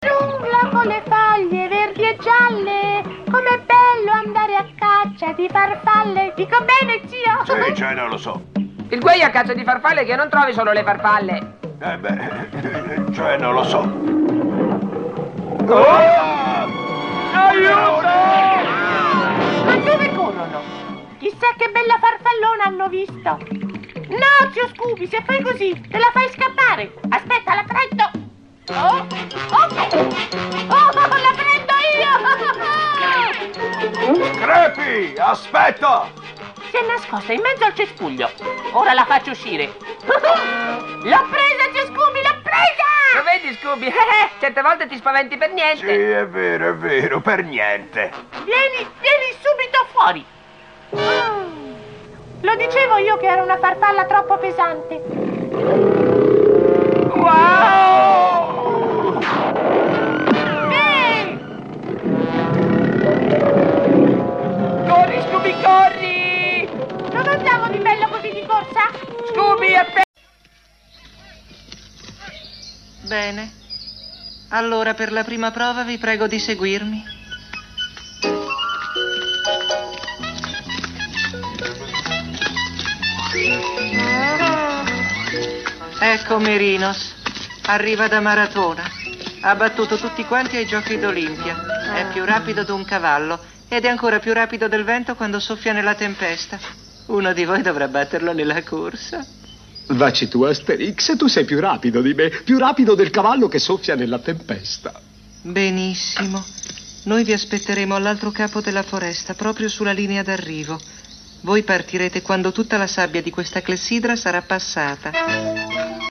nel cartone animato "Scooby e Scrappy Doo", in cui doppia Scrappy, e nel film d'animazione "Le 12 fatiche di Asterix" in cui doppia Caius Pupus.